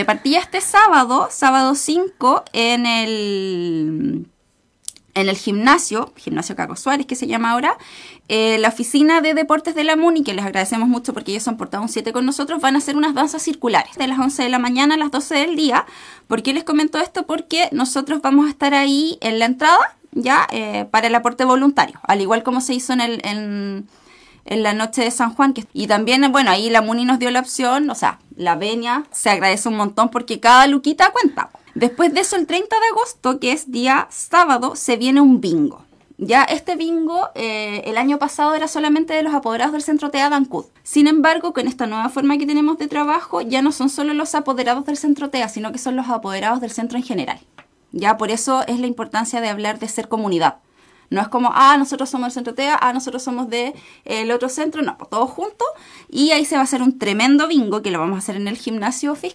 A ello se sumará un gran bingo solidario programado para el 30 de agosto, también en el recinto de calle Esmeralda, instancia que promete reunir a cientos de vecinos. De todo aquello, conversó con Radio Estrella del Mar